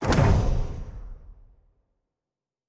pgs/Assets/Audio/Fantasy Interface Sounds/Special Click 16.wav at master
Special Click 16.wav